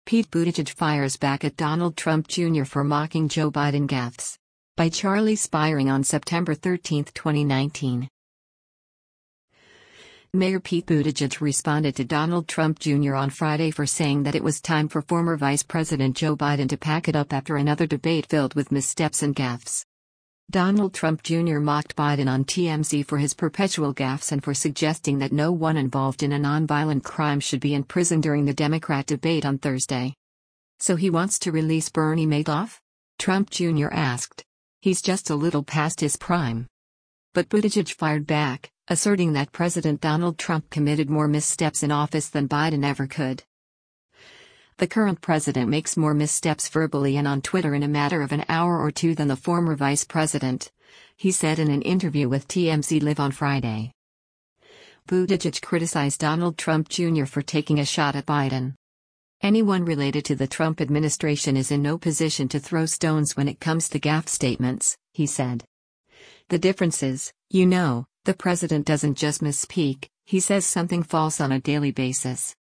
“The current president makes more missteps verbally and on Twitter in a matter of an hour or two than the former vice president,” he said in an interview with TMZ Live on Friday.